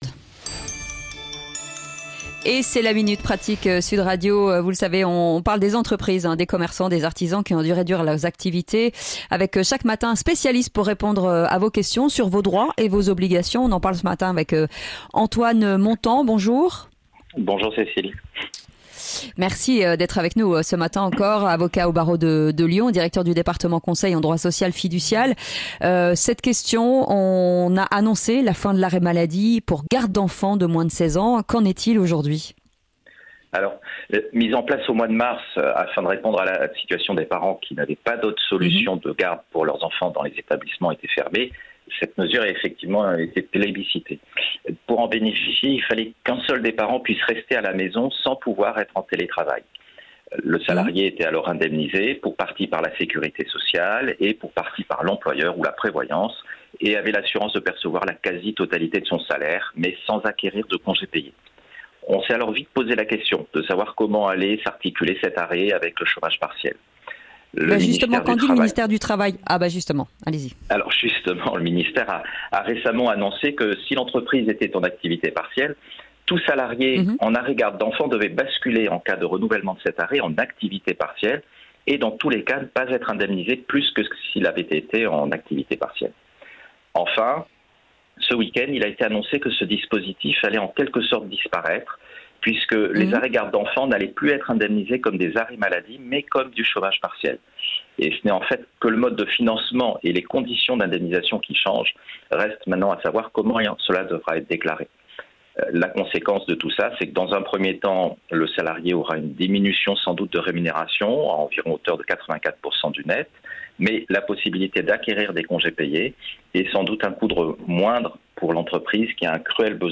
La minute pratique - A 9 heures, chaque jour dans le Grand Matin Sud Radio, des spécialistes Fiducial vous répondent.